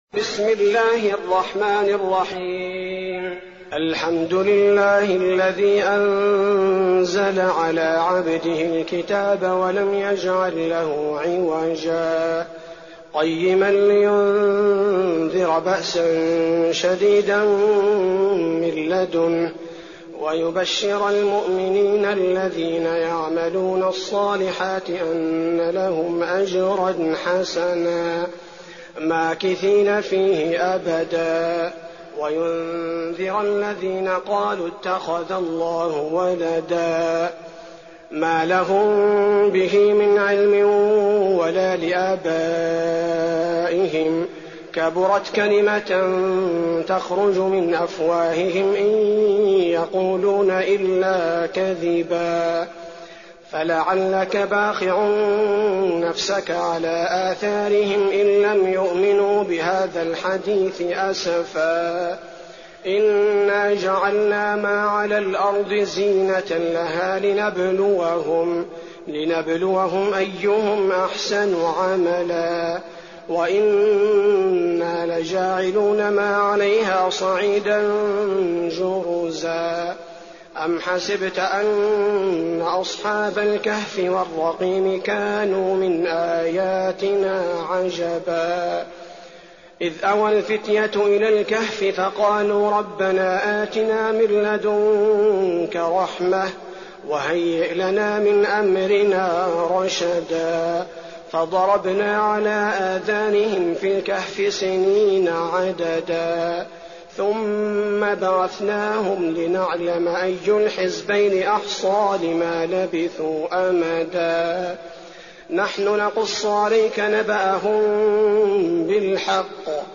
تراويح الليلة الخامسة عشر رمضان 1419هـ من سورة الكهف (1-82) Taraweeh 15th night Ramadan 1419H from Surah Al-Kahf > تراويح الحرم النبوي عام 1419 🕌 > التراويح - تلاوات الحرمين